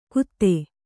♪ kutte